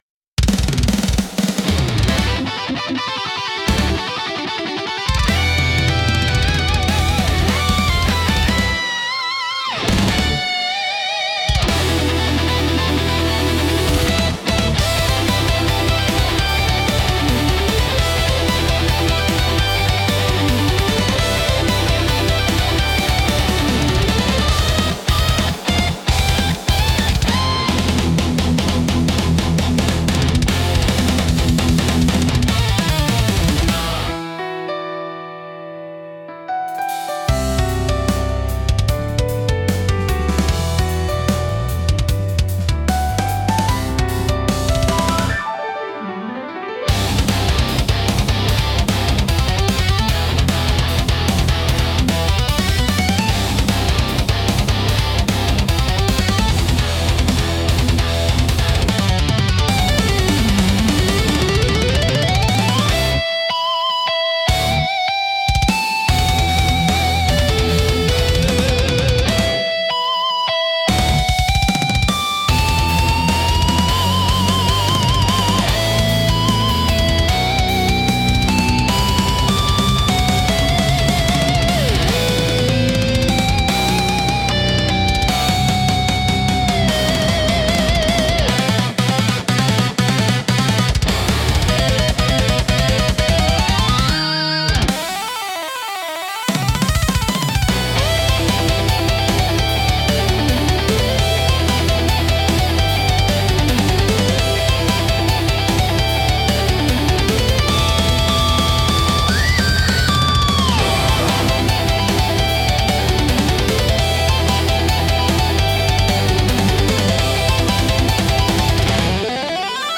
熱狂的で迫力あるサウンドが勝負や決戦の場面を盛り上げ、プレイヤーや視聴者の集中力と興奮を引き出します。